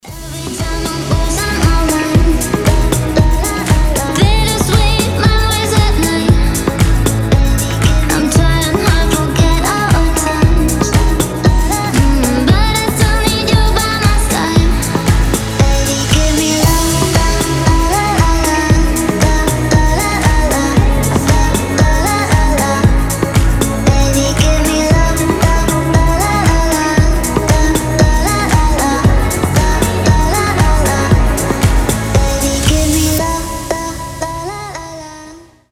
• Качество: 320, Stereo
deep house
грустные
милые
мелодичные
красивый женский голос
детский голос